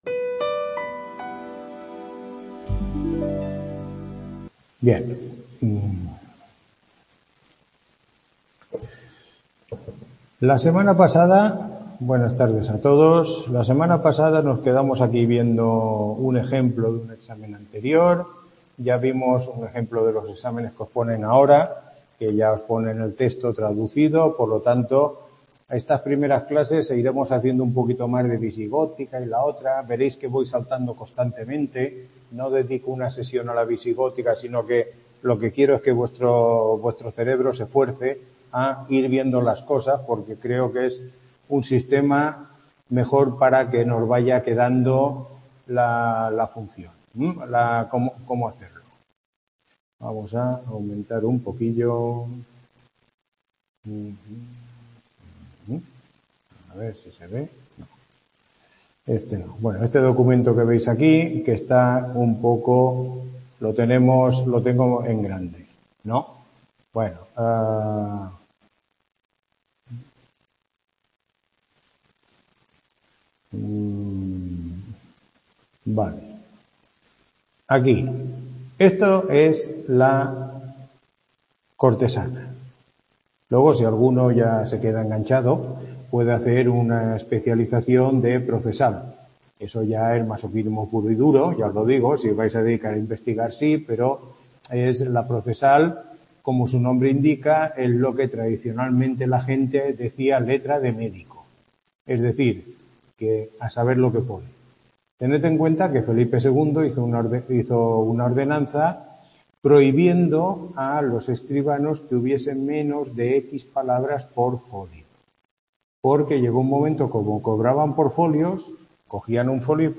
Tutoría 02